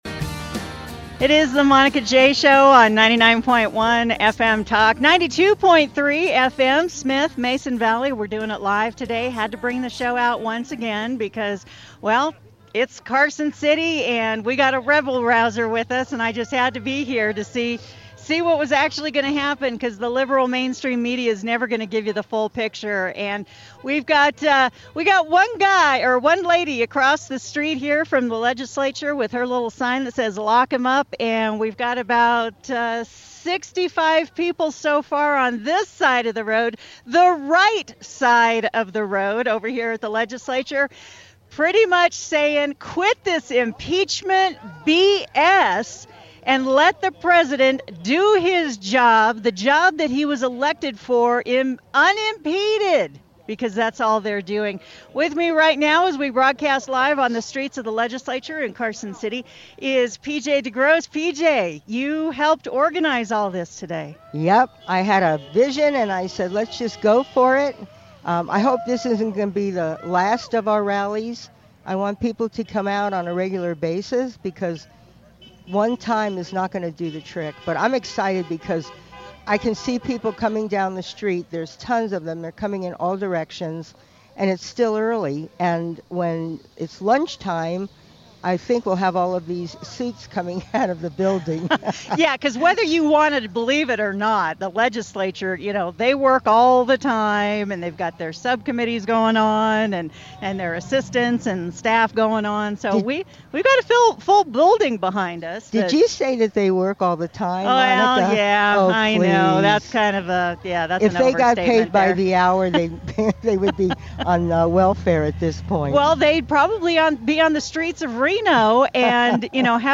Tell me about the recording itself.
Live from Carson City.